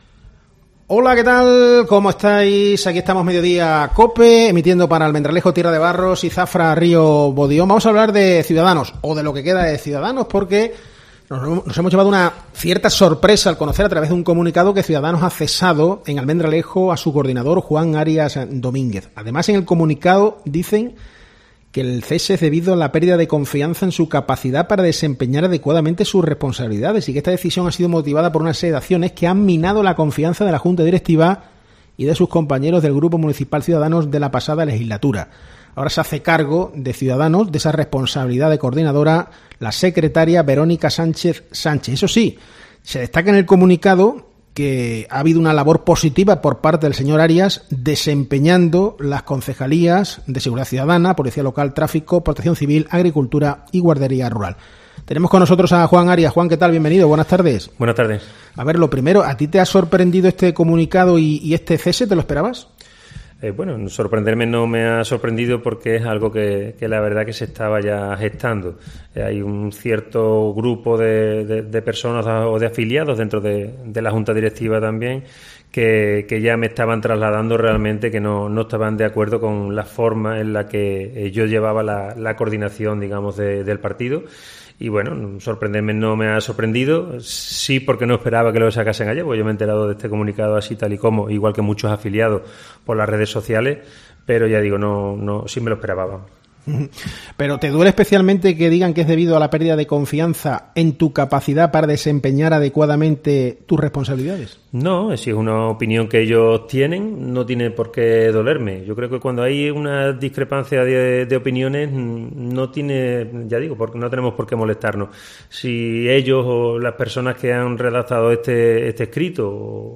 En COPE hemos hablado con él.